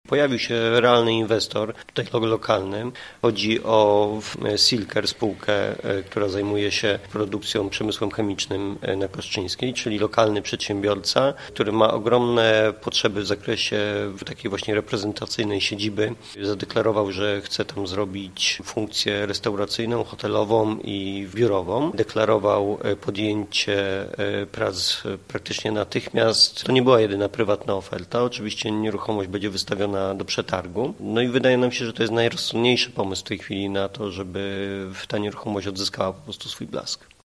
Mówi wiceprezydent Jacek Szymankiewicz: